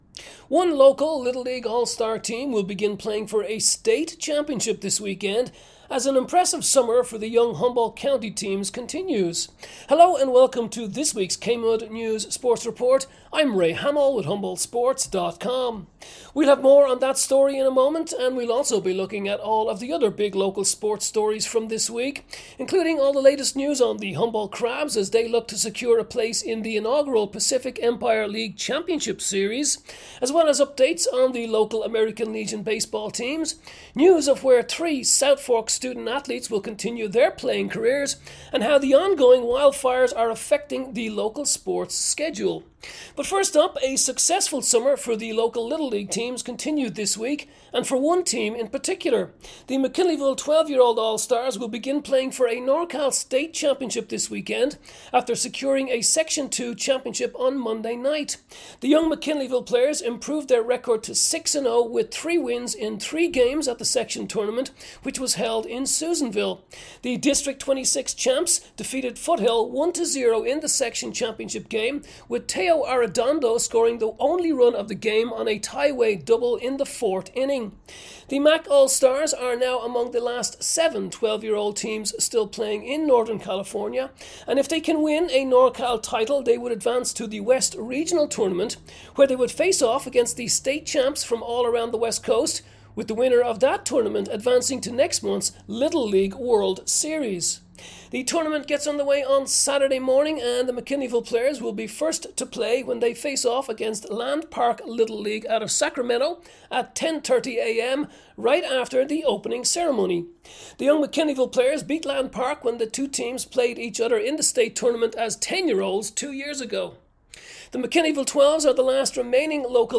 July 18 KMUD Sports Report